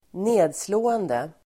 Uttal: [²n'e:dslå:ende]